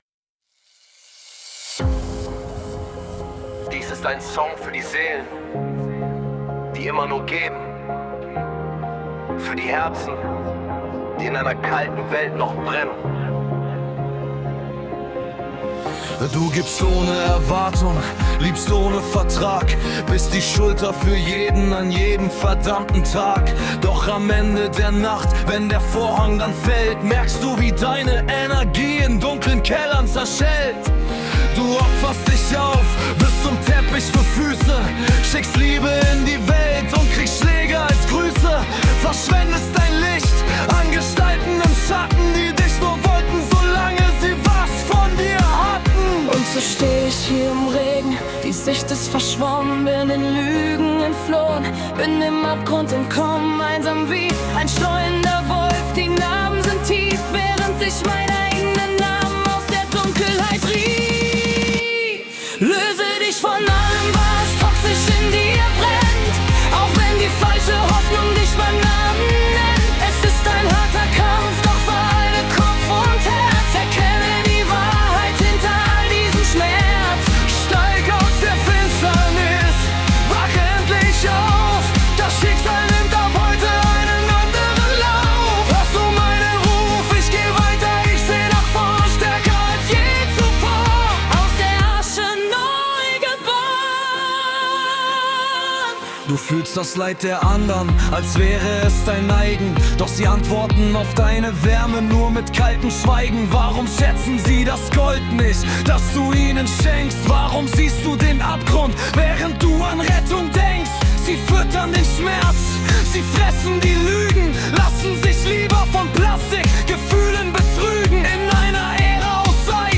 House, PartyMix, MaleFemale Duett Alternative